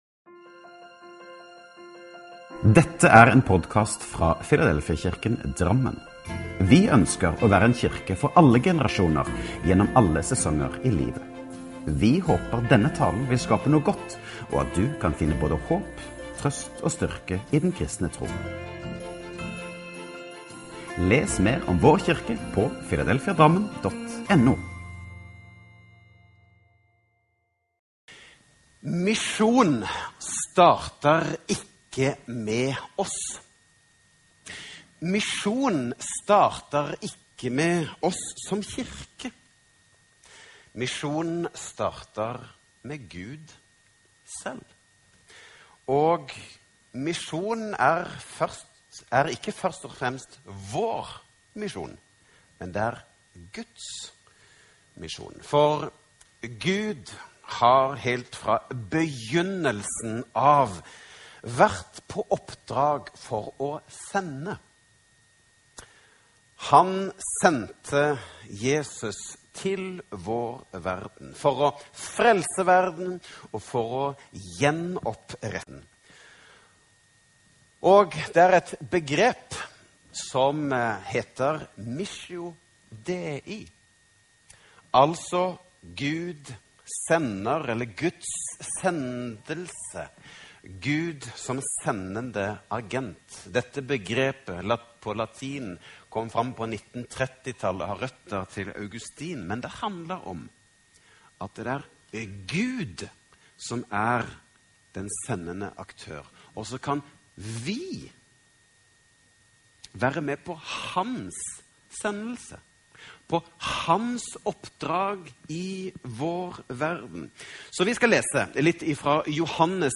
… continue reading 511 эпизодов # Kristen # Filadelfia # Drammen # Pinsevenn # Pinsemenighet # Preken # Religion # Kristendommen # Kirke # Filadelfiakirken Drammen # Filadelfiakirken # Menighet # Taler